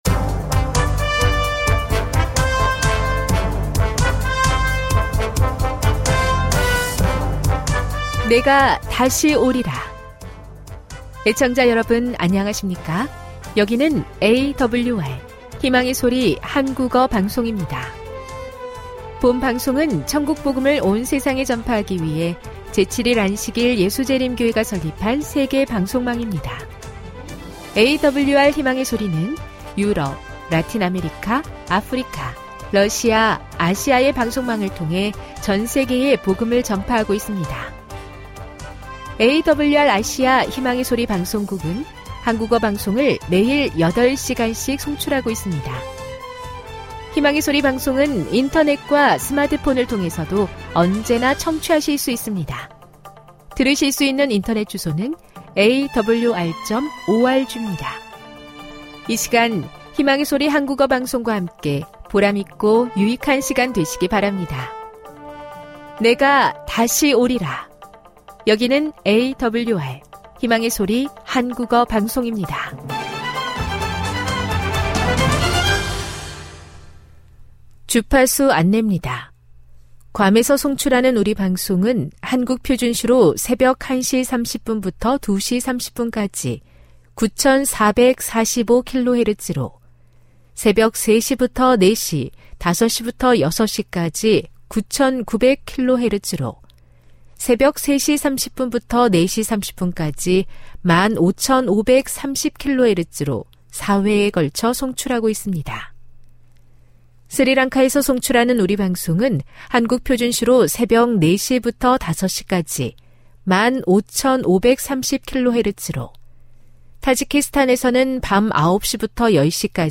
1 대예배 58:46